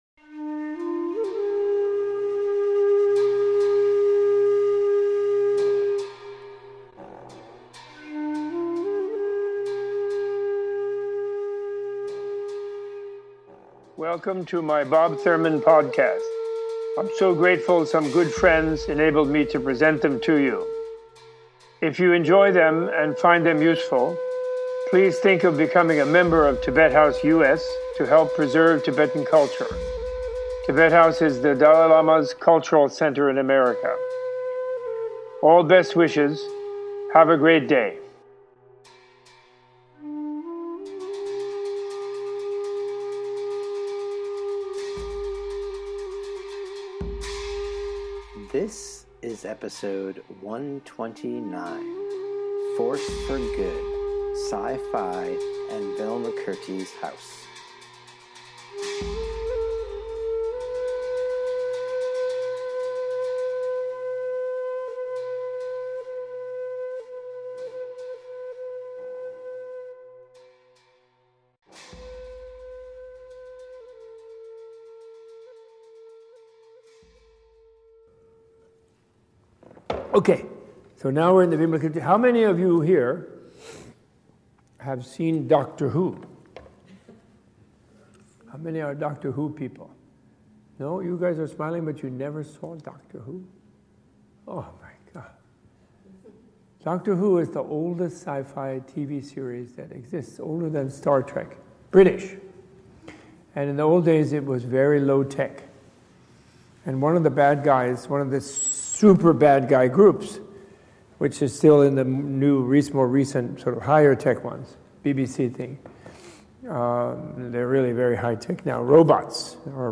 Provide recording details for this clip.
This talk was recorded during the 2016 Force For Good Class #6 at Tibet House US in New York City in February 2016.